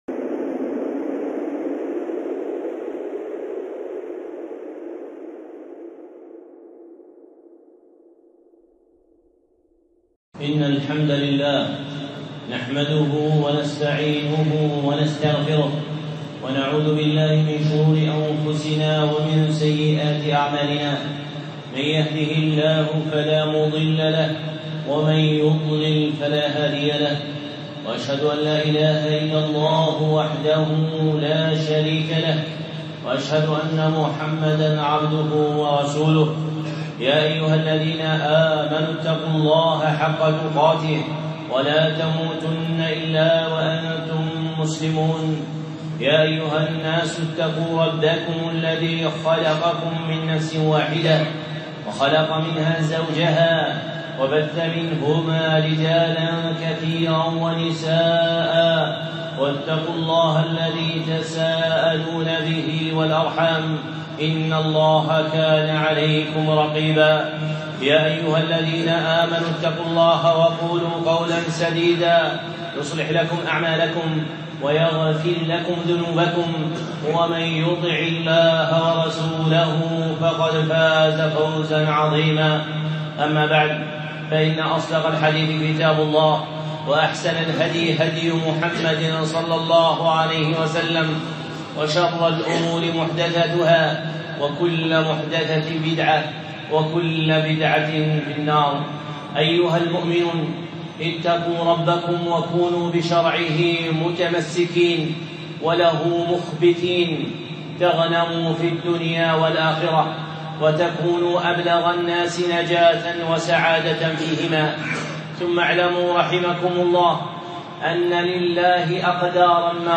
خطبة (الصبر عند المصائب) الشيخ صالح العصيمي